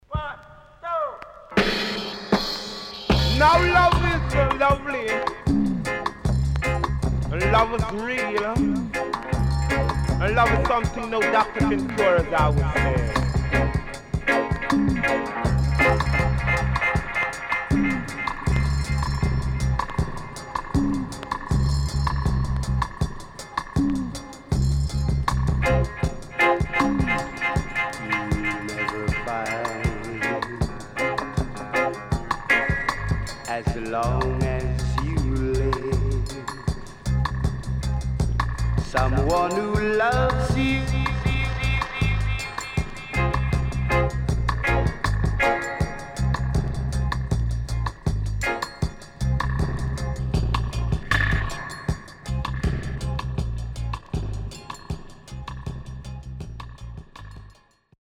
CONDITION SIDE A:VG+
Deejay Cut
SIDE A:少しチリノイズ、プチノイズ入ります。